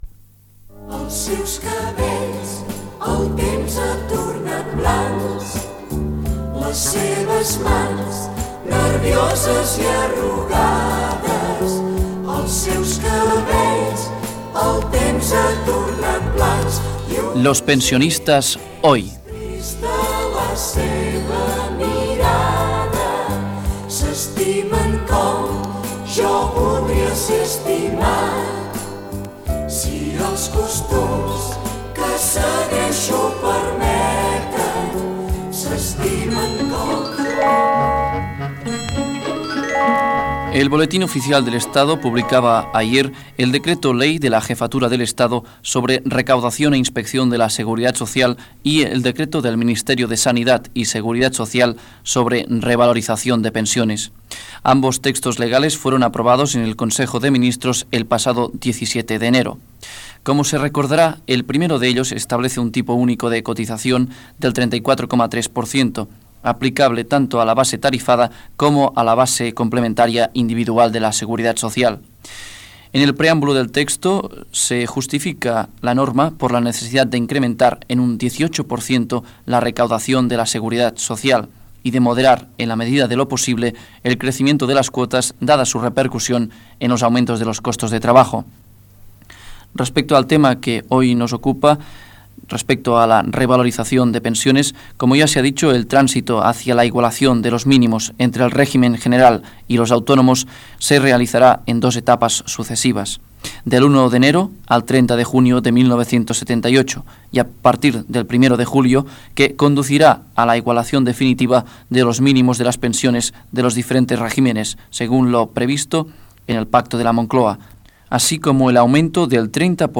Reportatge "Los pensionistas hoy" sobre la revalorització de les pensions a l'Estat espanyol i la situació dels pensionistes
Informatiu